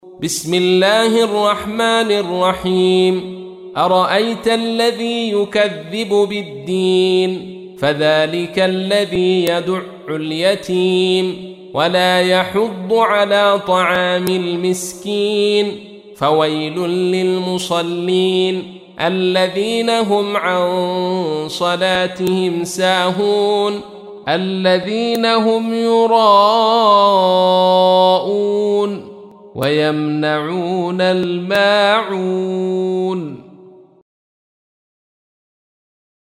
تحميل : 107. سورة الماعون / القارئ عبد الرشيد صوفي / القرآن الكريم / موقع يا حسين